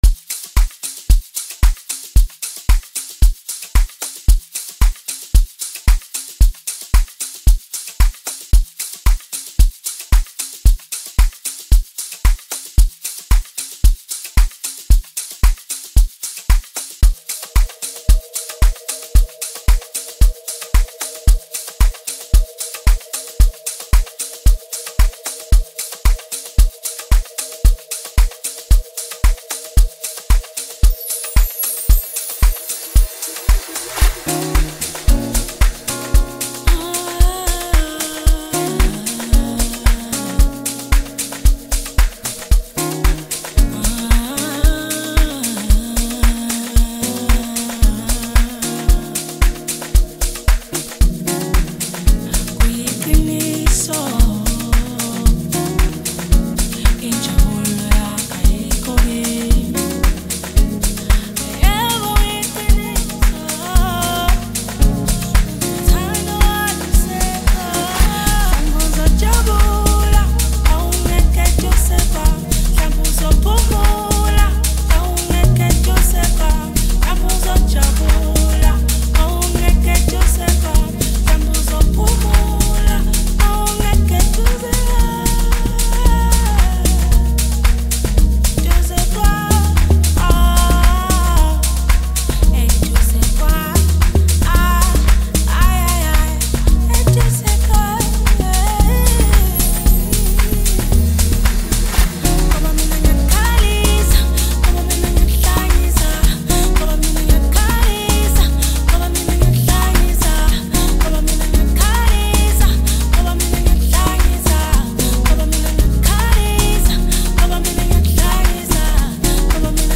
and many others to create this dance music extravaganza.